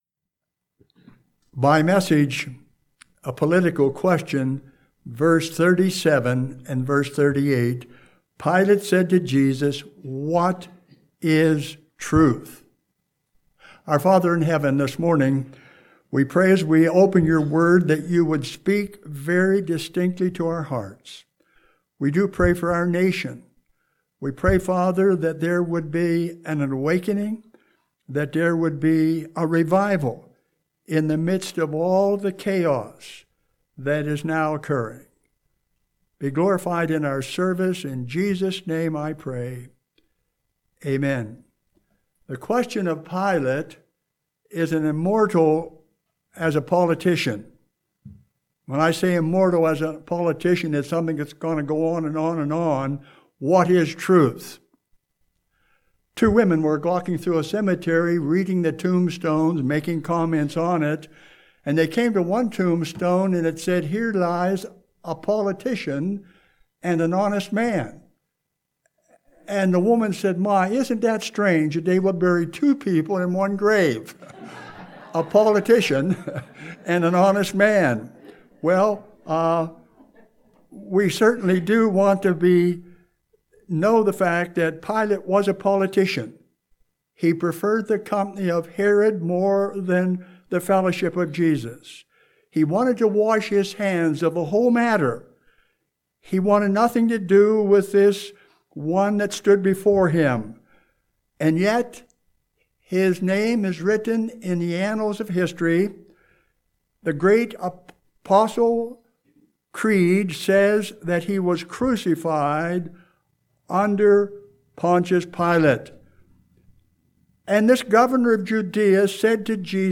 Home › Sermons › August 16, 2020